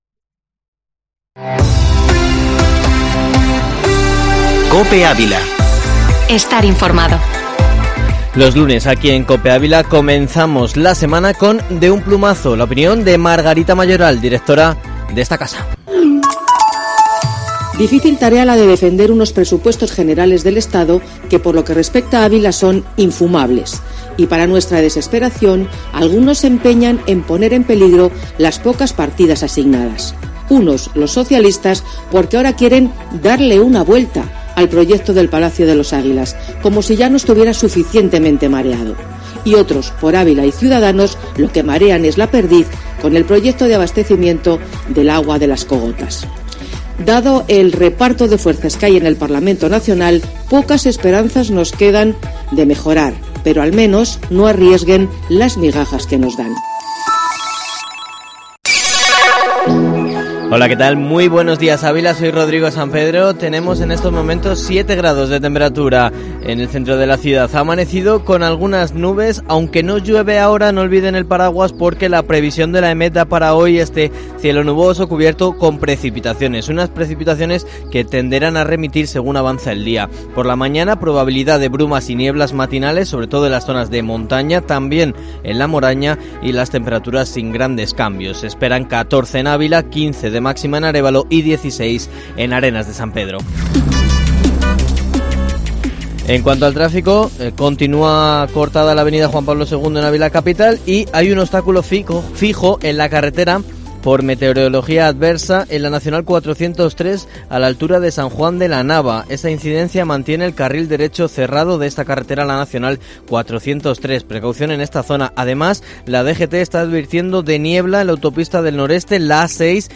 Informativo matinal Herrera en COPE Ávila 09/11/2020